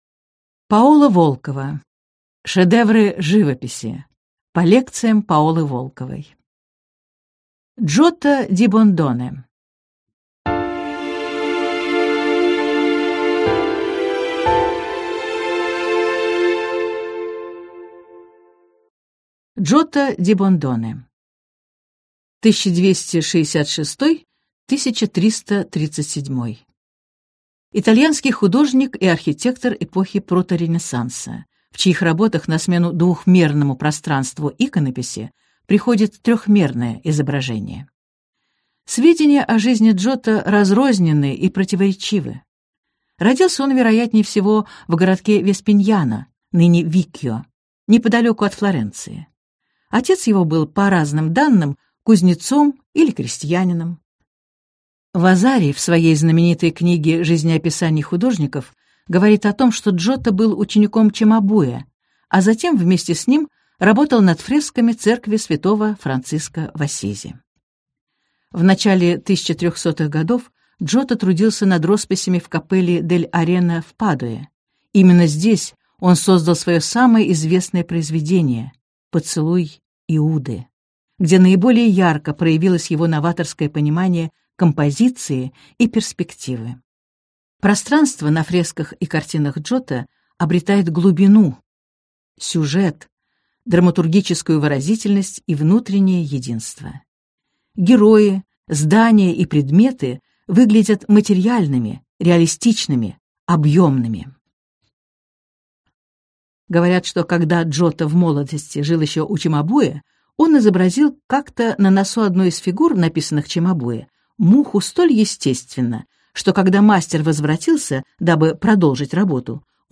Аудиокнига Шедевры живописи. По лекциям Паолы Волковой | Библиотека аудиокниг